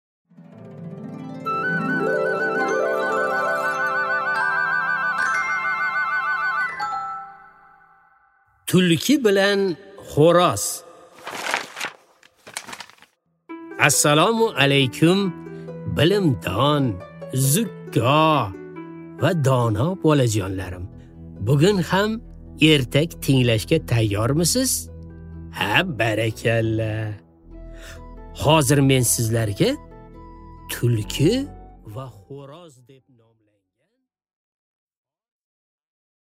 Аудиокнига Tulki va хo'roz | Библиотека аудиокниг